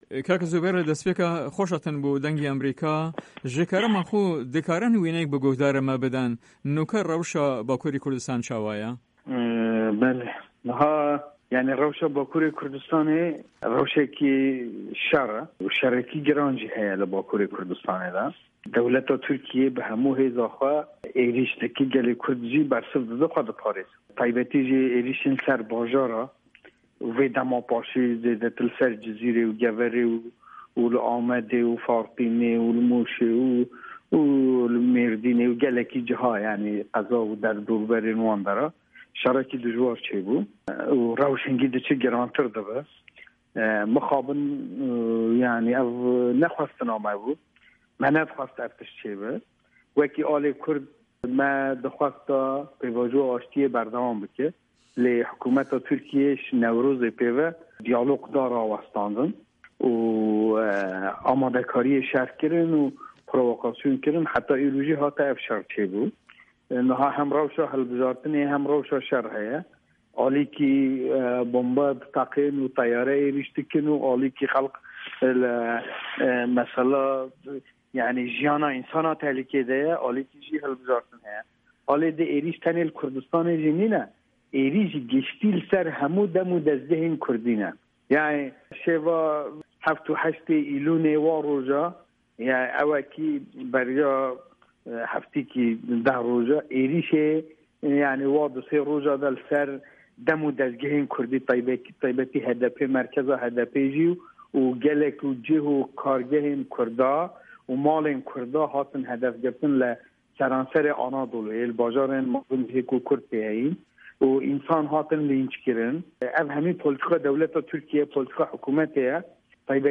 زوبێر ئاێدار ئەندامی کۆنسەی رێڤەبەریا کۆما جڤاکن کوردستان KCK لە هەڤپەیڤینێکدا لەگەڵ بەشی کوردی دەنگی ئەمەریکا دەڵێت" باکوری کوردستان لە باری شەردایە و بەڵکو شەرێکی زۆر توند، حکومەتی تورکیا بە هەموو تواناکانیەوە هێڕش دەبات و گەلی کوردیش ناچارە بۆ مانەوەی بەرگری لە خۆ بکات.